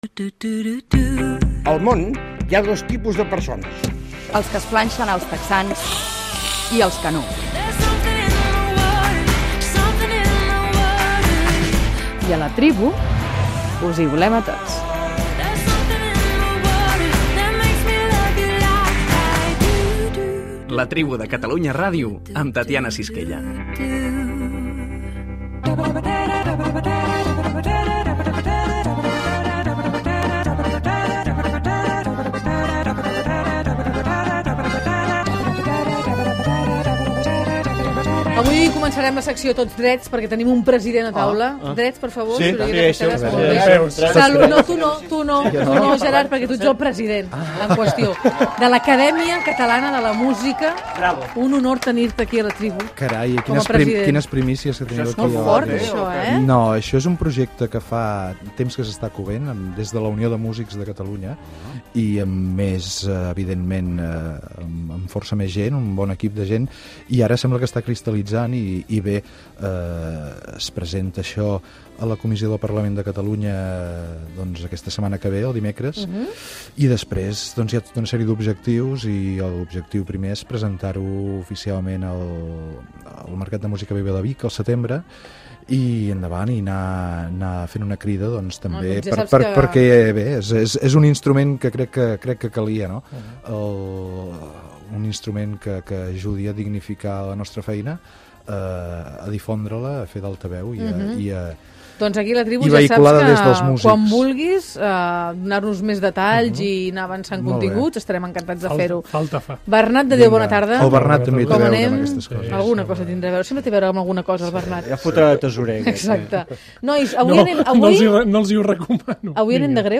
Indicatiu del programa